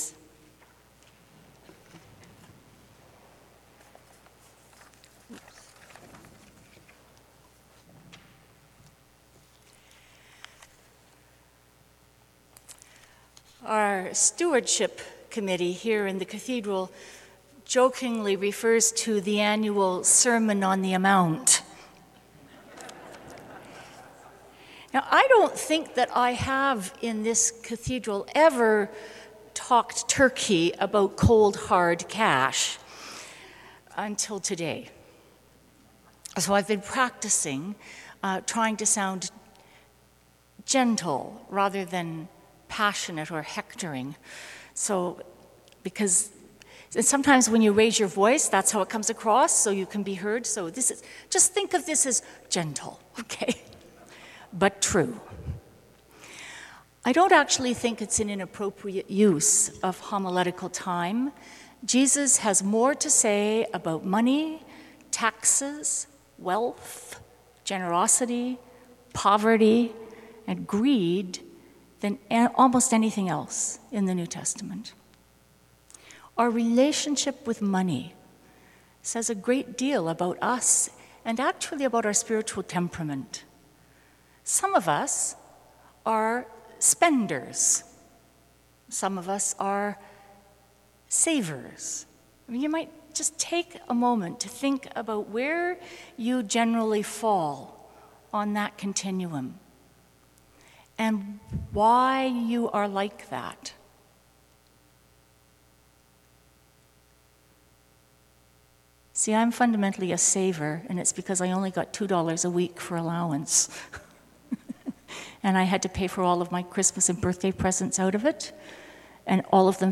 Sermon Audio | Christ Church Cathedral
"Sermon on the Amount": 9.15 a.m. service